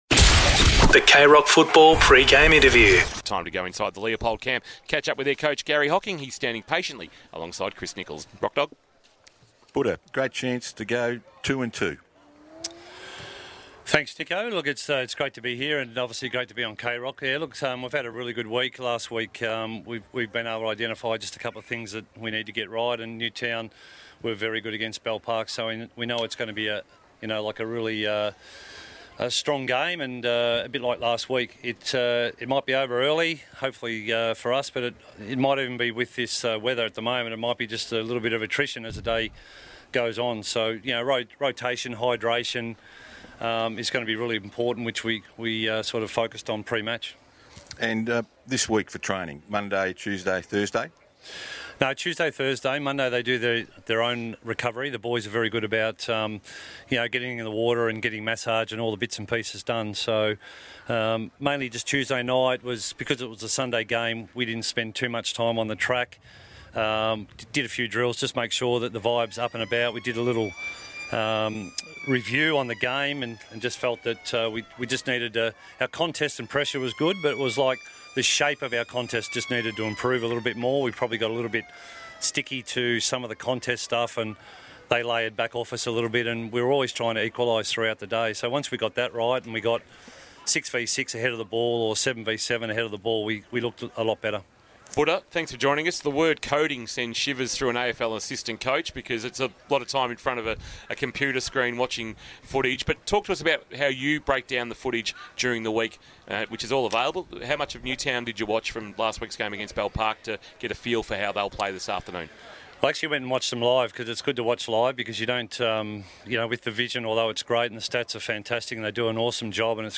2022 - GFL ROUND 2 - NEWTOWN & CHILWELL vs. LEOPOLD: Pre-match Interview - Garry Hocking (Leopold coach)